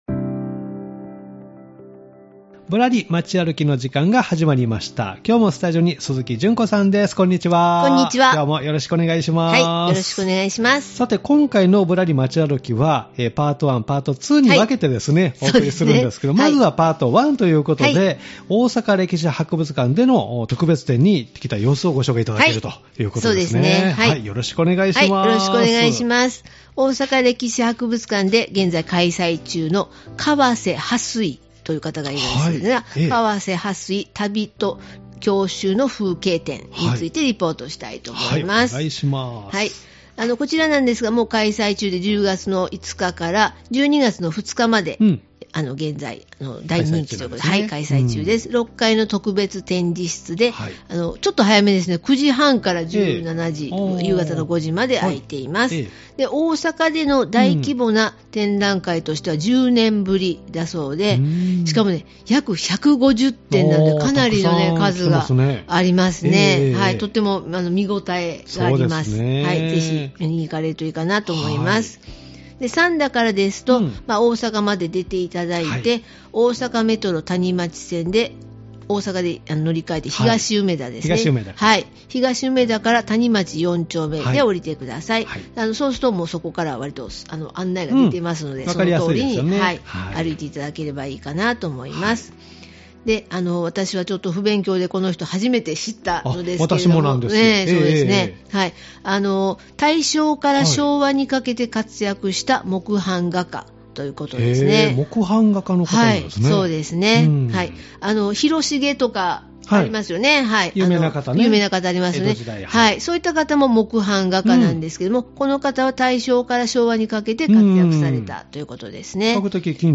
まずは、 大阪歴史博物館 で開催中の特別展「川瀬巴水 旅と郷愁の風景」（会期：10月5日-12月2日）についてリポートしてもらいました！大正から昭和にかけて活躍した木版画家・川瀬巴水（1883-1957［明治16-昭和32］年）の作品約150点を観ることができます。